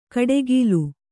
♪ kaḍegīlu